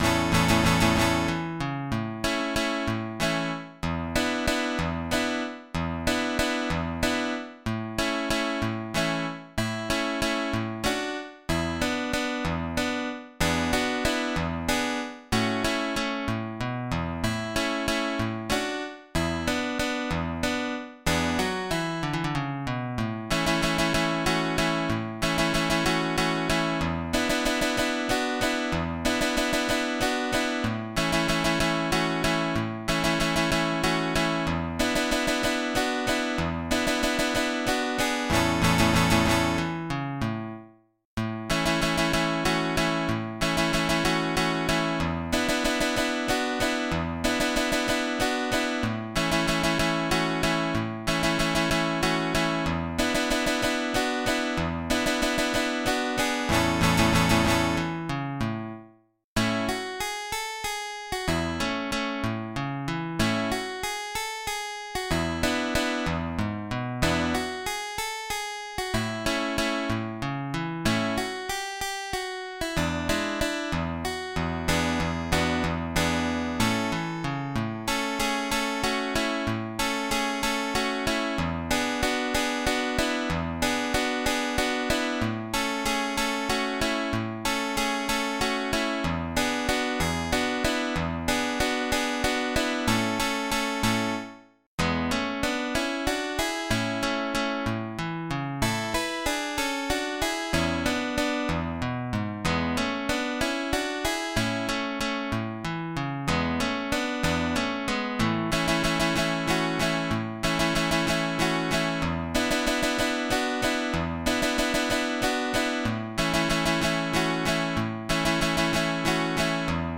Brani da non perdere (Flamenco):
alba_op32_Jota-Aragonesa.mid.mp3